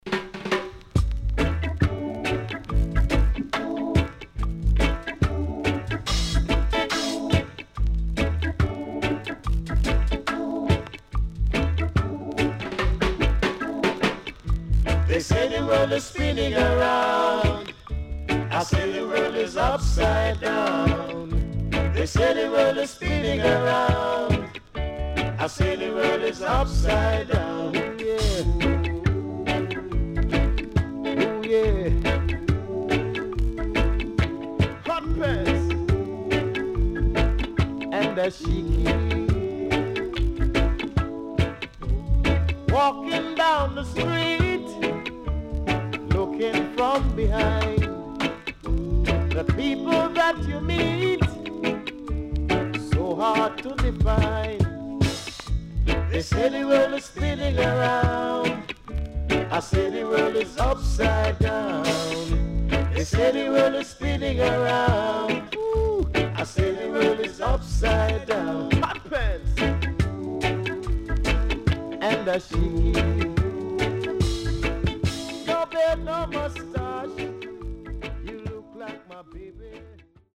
Nice Early Reggae Vocal